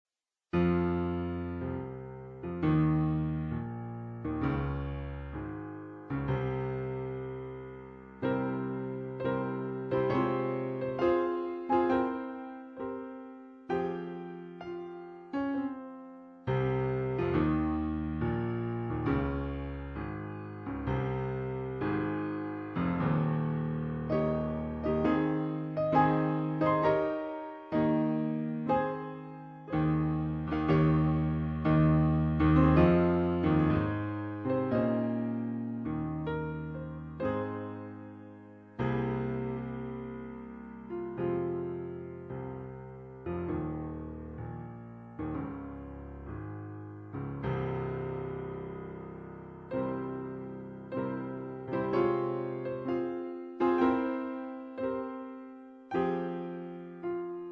Piano Rolls
MIDI recording
Chopin Fantasie Op, 49 in F minor
rendered from MIDI files that were produced from an optical scan of piano rolls
Original (dry) sample mp3 1.0 MB PureVerb ™ enhanced mp3 (2.6 MB)
Chopin_fant_dry.mp3